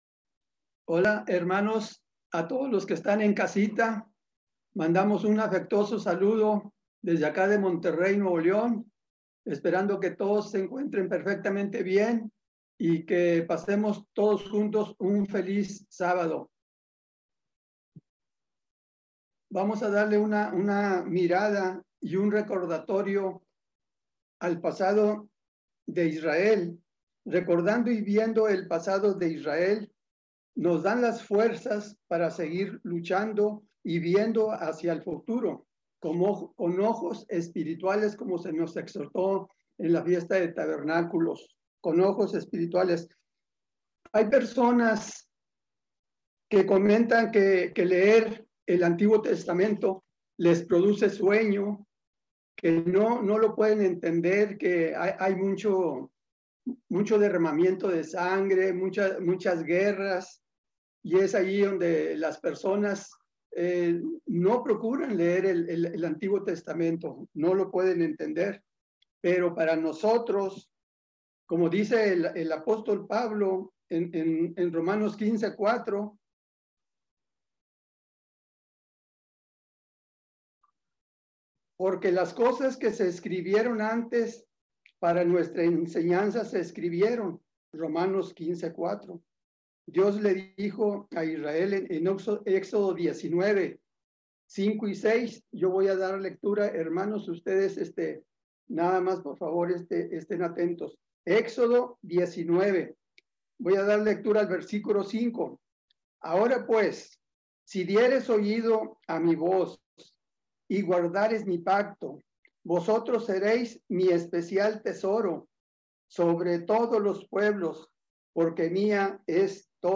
Dios el Padre es muy claro en cuanto a lo que requiere de nosotros para ser hijos suyos en su Reino. La desobediencia tiene un alto precio para el cristiano convertido. Mensaje entregado el 24 de octubre de 2020.